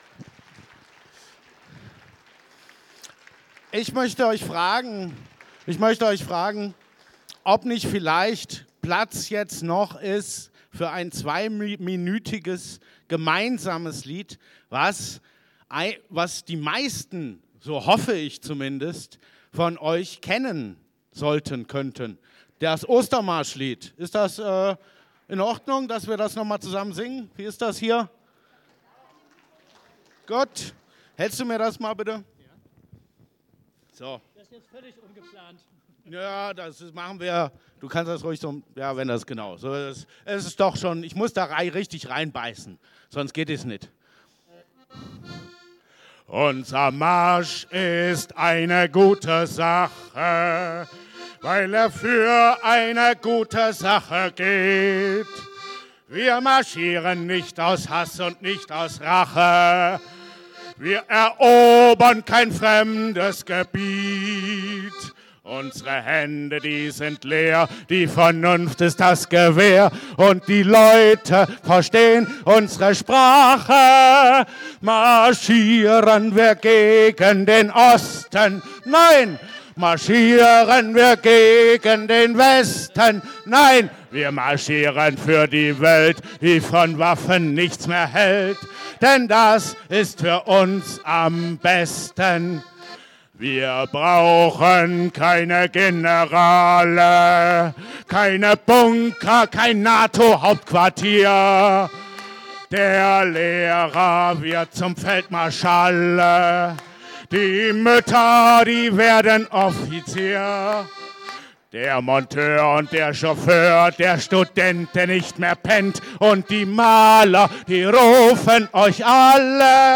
Kulturbeiträge als Abschluss des Ratschlages